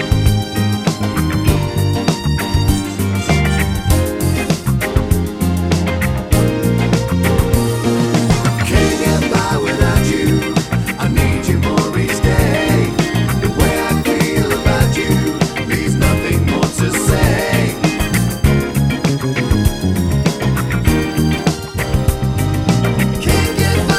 One Semitone Down Disco 3:18 Buy £1.50